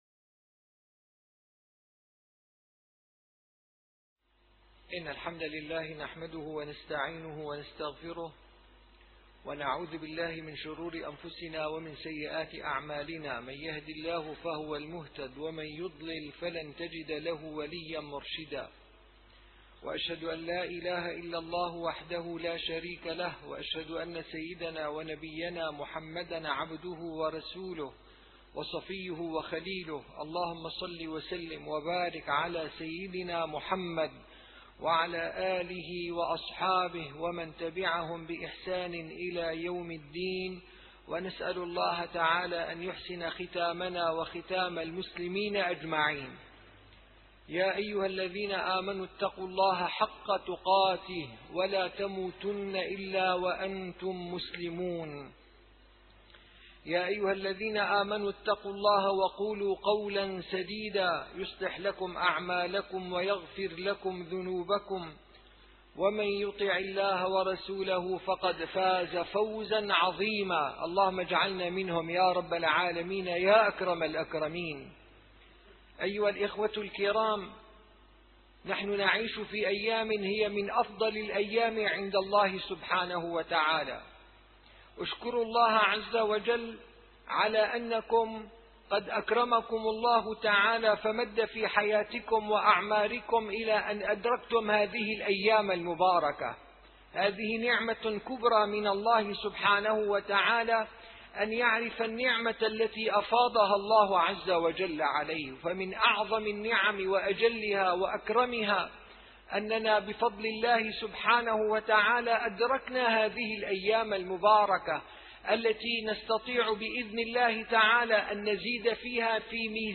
- الخطب - فضل العشر من ذي الحجة والأضحية